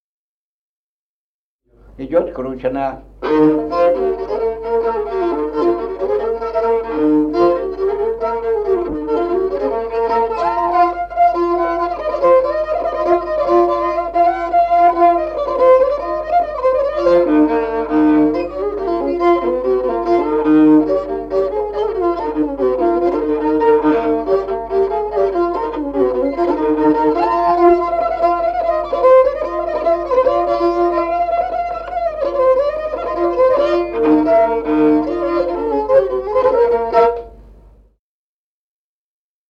Музыкальный фольклор села Мишковка «Кручена», репертуар скрипача.